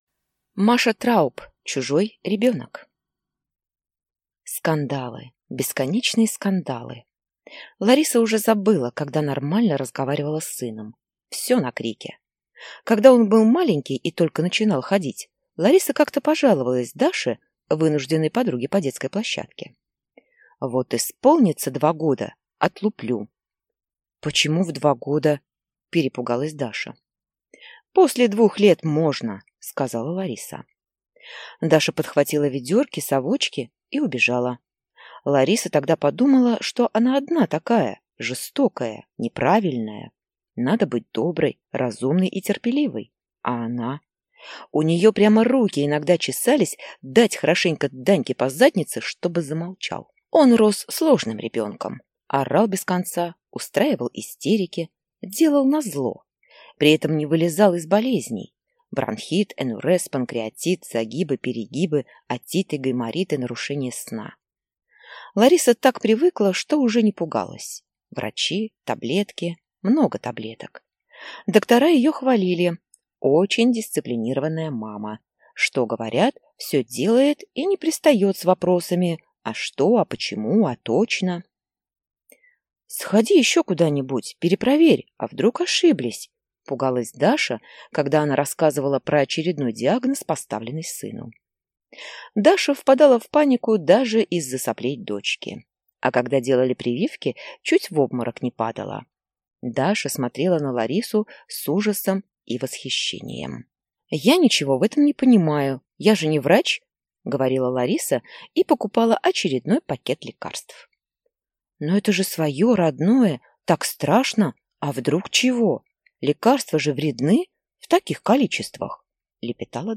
Аудиокнига Чужой ребенок | Библиотека аудиокниг